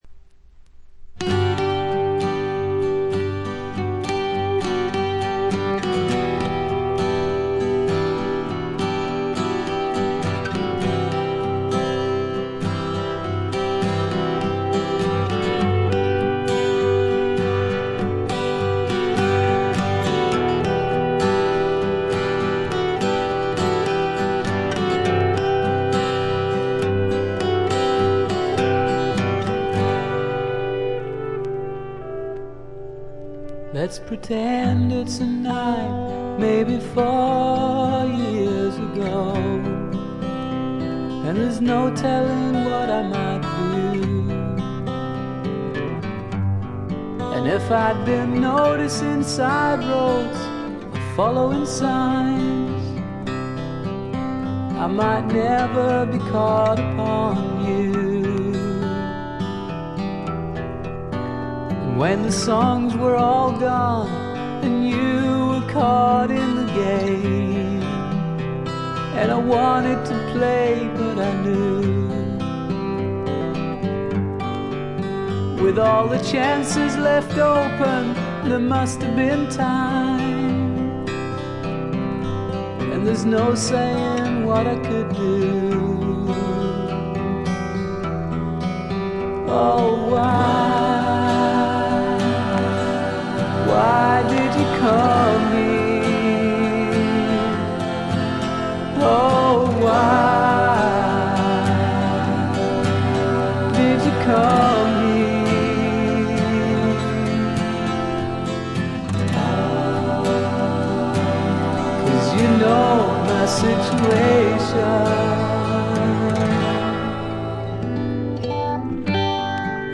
プカプカと牧歌的で枯れた味わいです。
mandolin, cello, mandola